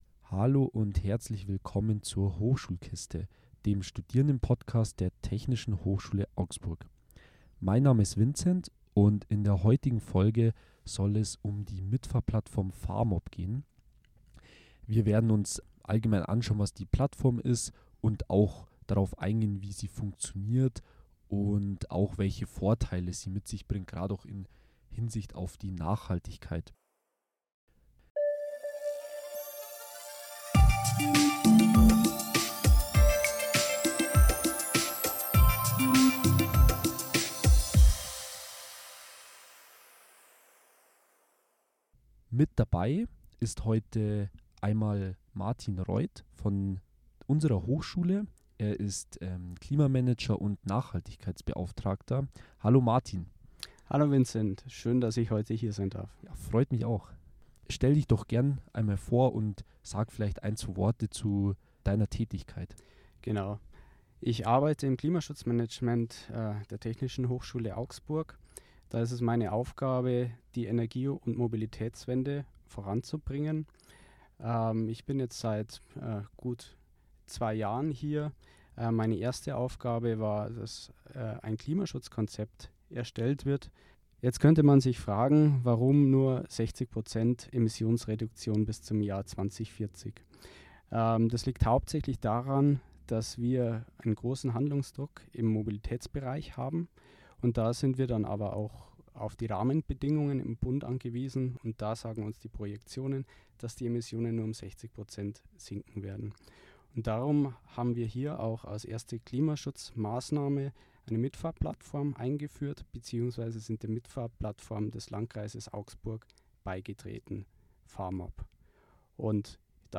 Gemeinsam sprechen wir über die Mitfahrplattform fahrmob.